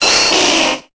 Cri d'Herbizarre dans Pokémon Épée et Bouclier.